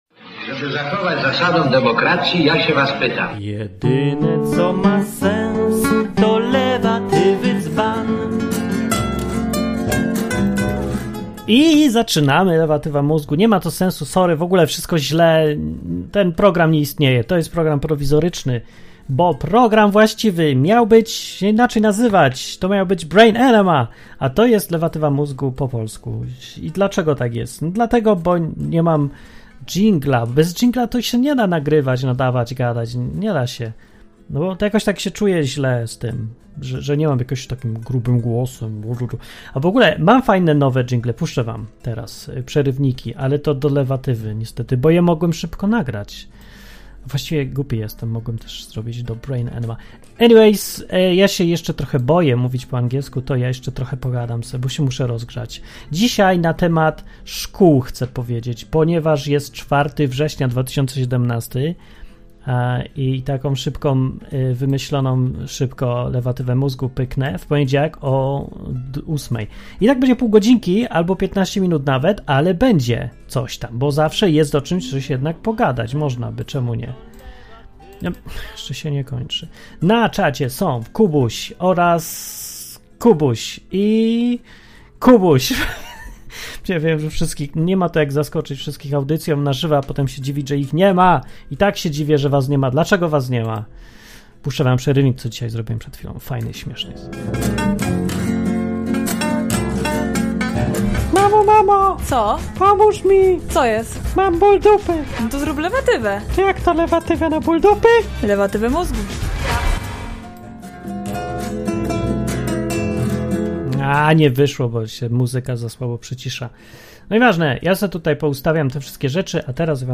Program satyryczny, rozrywkowy i edukacyjny.